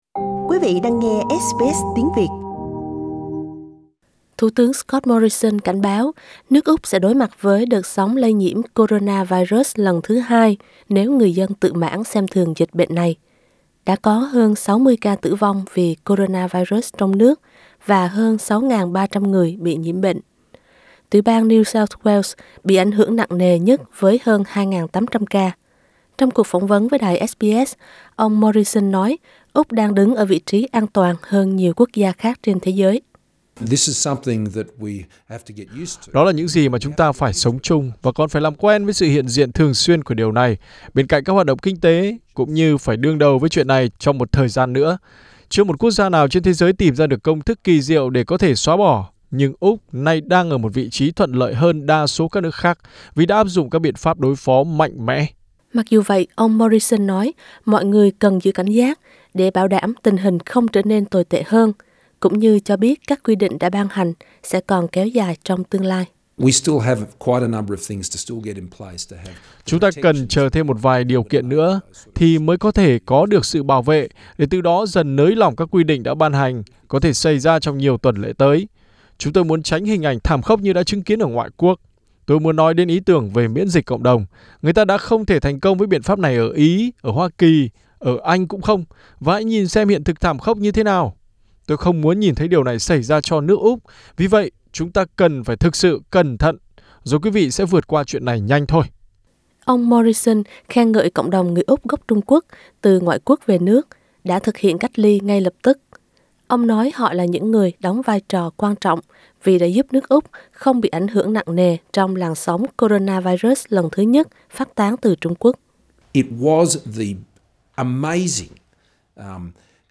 Trong cuộc phỏng vấn với đài SBS, Thủ tướng Scott Morrison nói Úc đang đứng ở vị trí an toàn hơn nhiều quốc gia khác. Ông chỉ trích tổ chức WHO vì đã ủng hộ Trung Quốc mở cửa các chợ buôn bán động vật sống, cũng như phê bình biện pháp ‘miễn dịch cộng đồng’ mất kiểm soát, dẫn tới sự bùng nổ dịch bệnh một cách thảm khốc ở ngoại quốc.